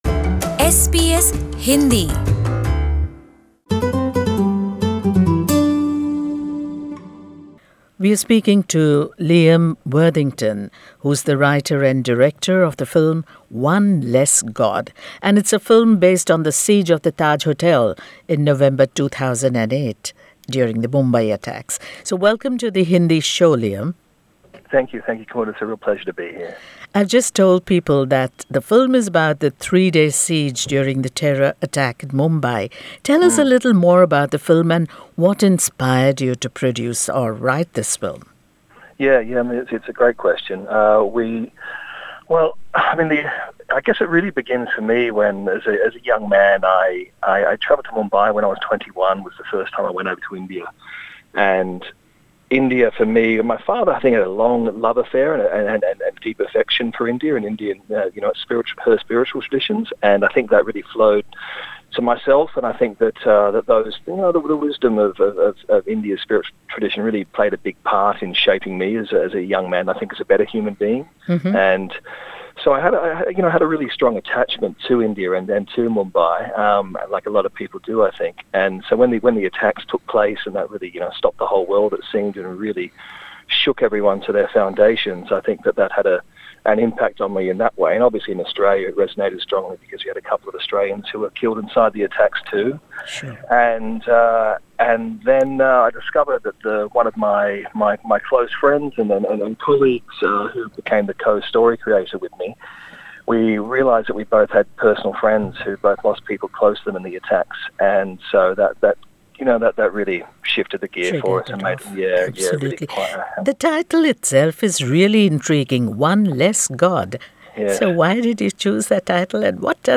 In an exclusive interview with the Hindi program